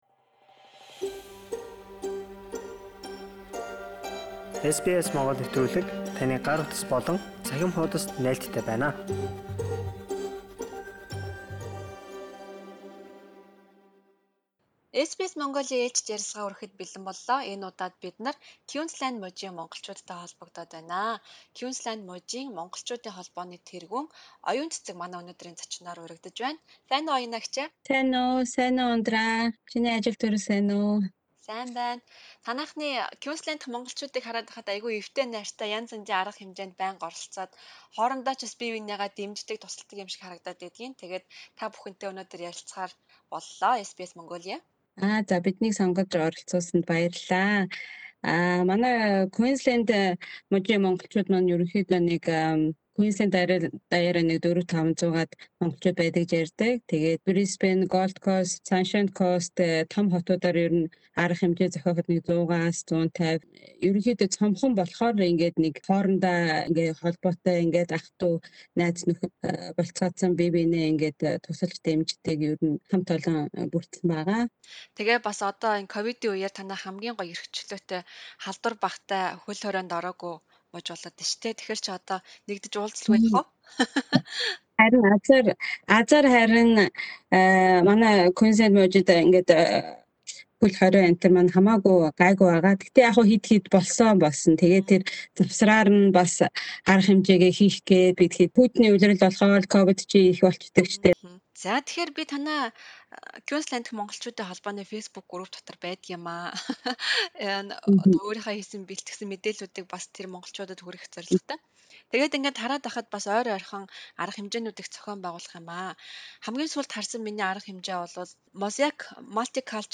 SBS Монгол хэлээр